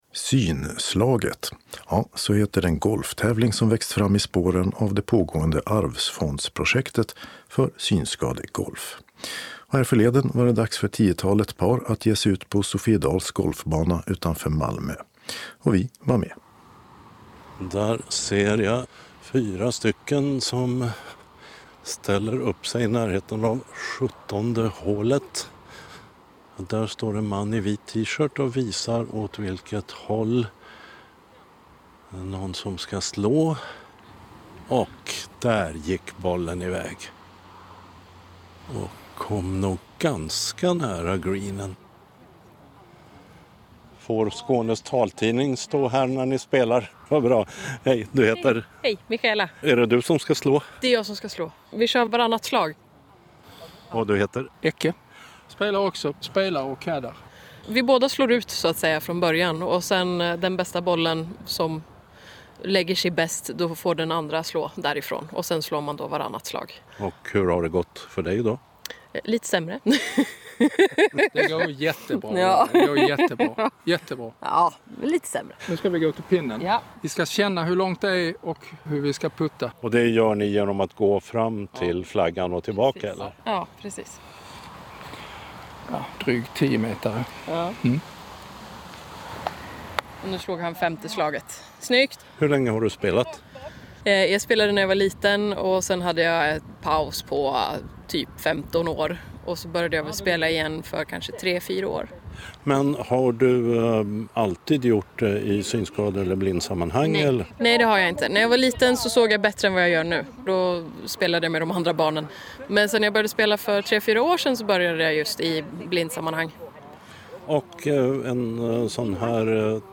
Klicka på knapparna nedan för att lyssna på reportage från Skånes taltidning samt för att läsa artikeln från Sydsvenskan om denna tävling.